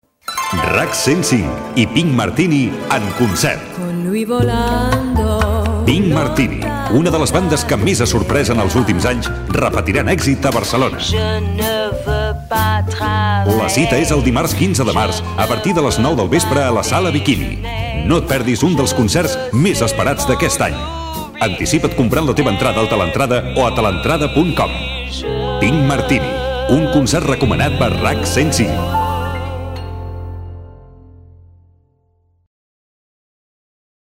Anunci de l'actuació de Pink Martini a la Sala Bikini Gènere radiofònic Publicitat